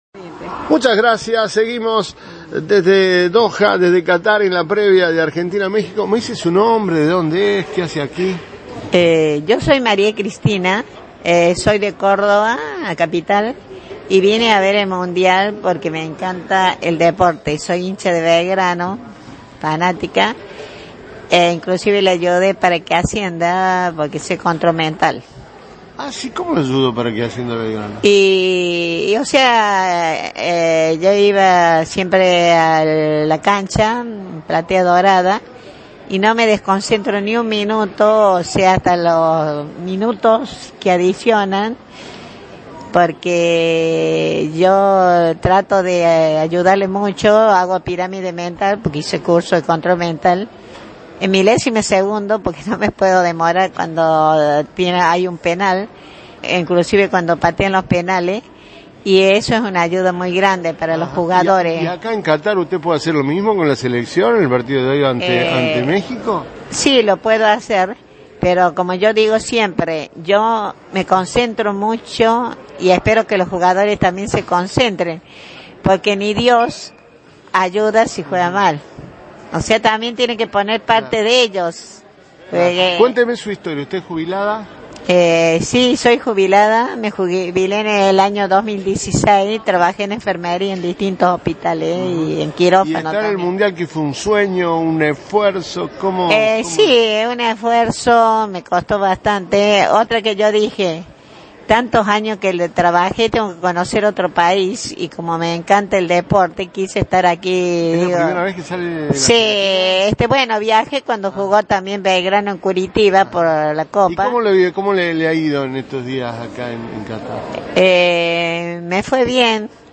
Los hinchas argentinos se hicieron escuchar cuando salieron los jugadores para el precalentamiento y expresaron un rotundo apoyo.